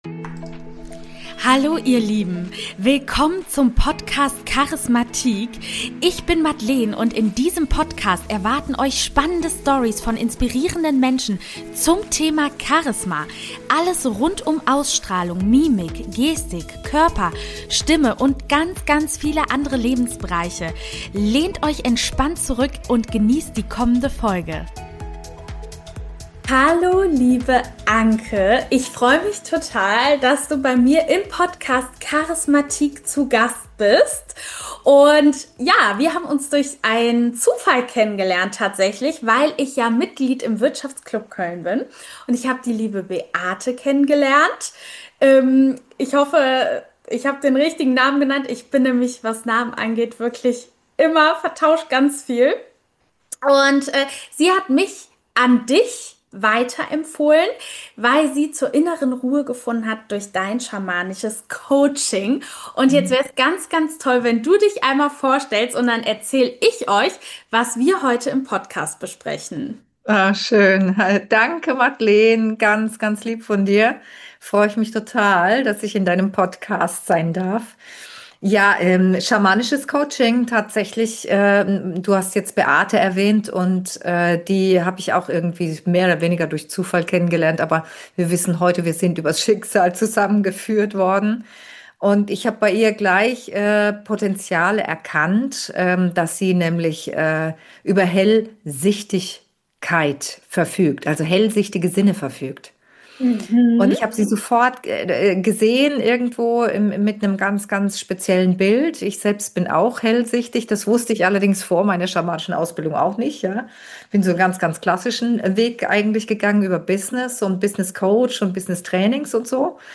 In unserem Gespräch tauchen wir ein in Themen wie Women Empowerment und schamanisches Coaching.